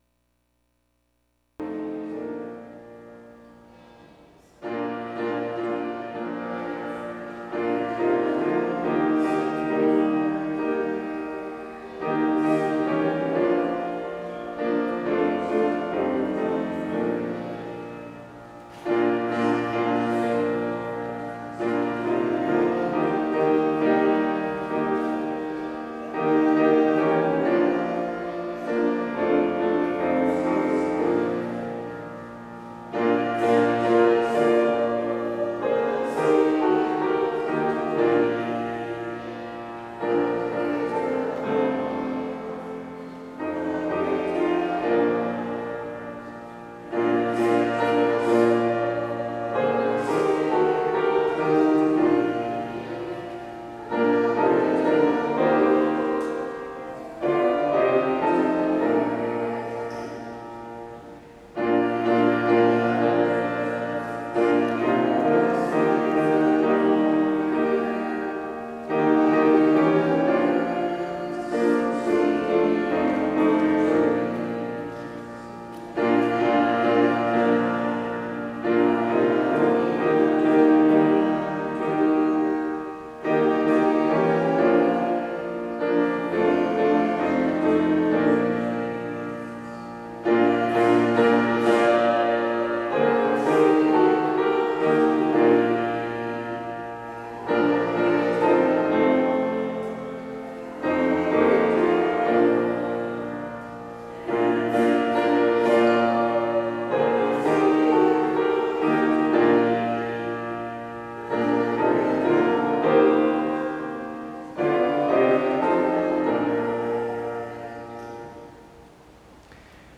Worship Service Sunday July 20, 2025